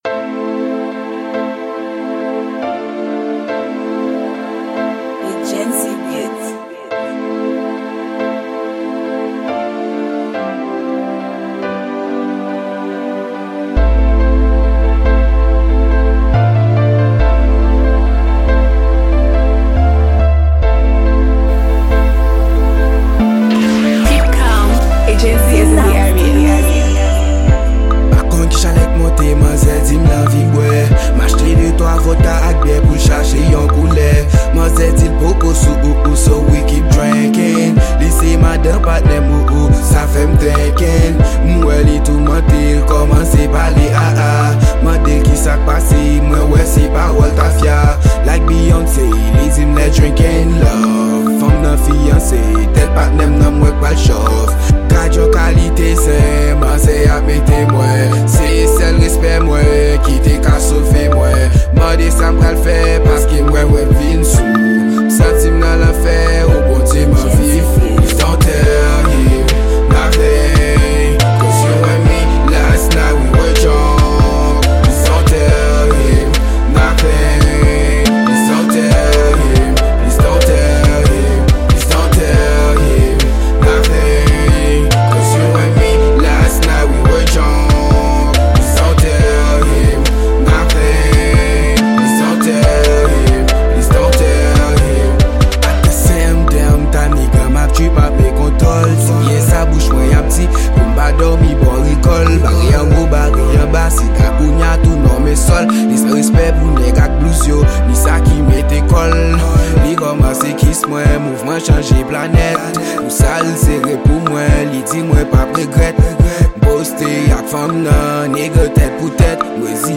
Genre: Rap.